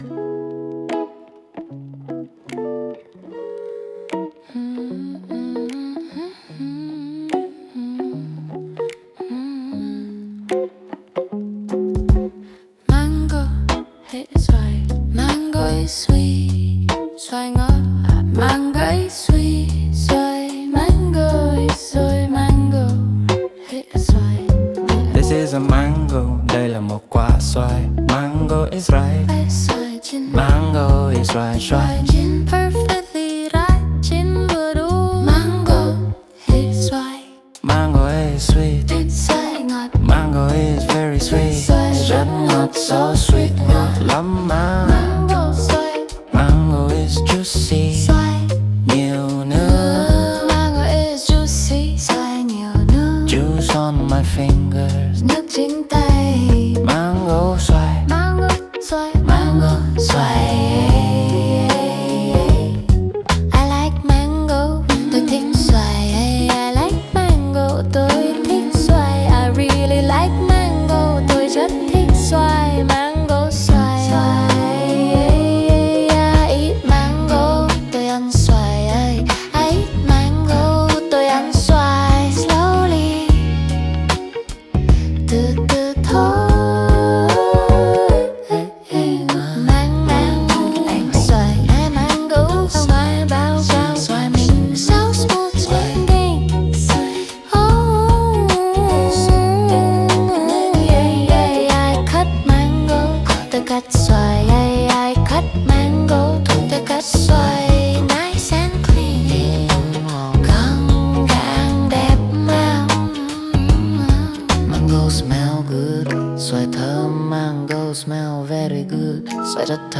Learn how to say Mango in Vietnamese via a Sexy Song